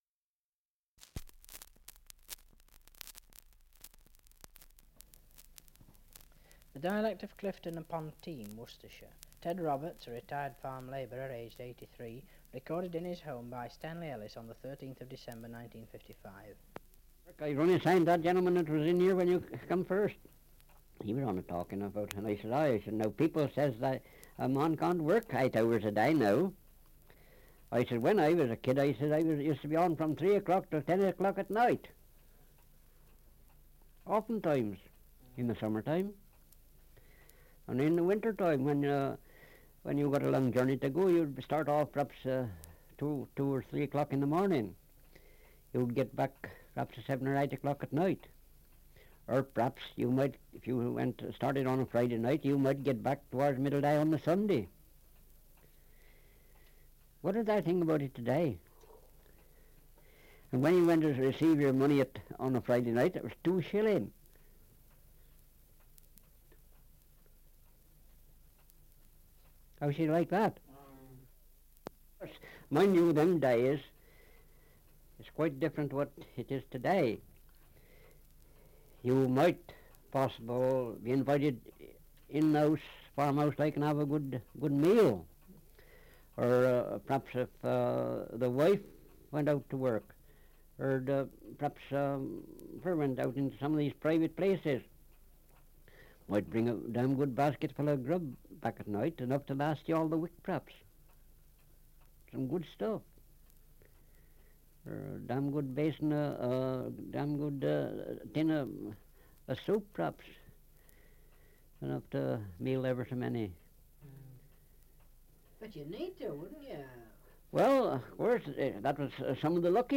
Survey of English Dialects recording in Clifton upon Teme, Worcestershire
78 r.p.m., cellulose nitrate on aluminium